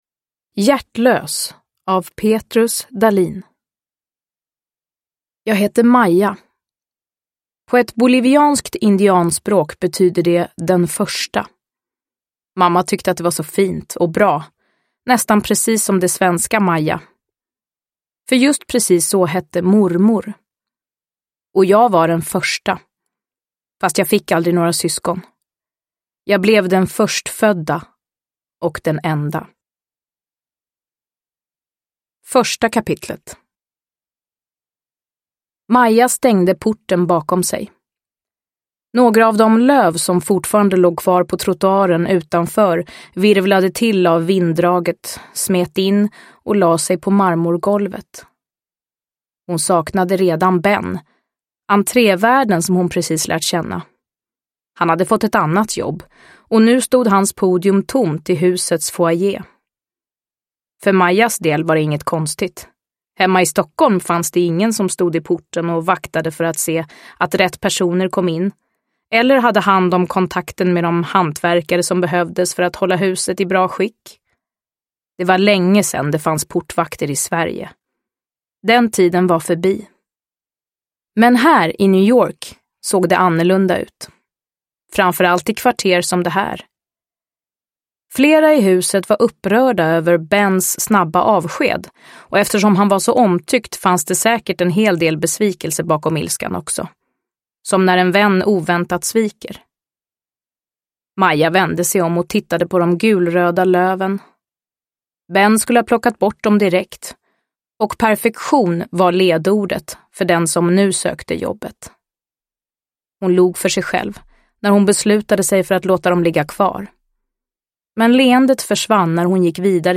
Hjärtlös – Ljudbok – Laddas ner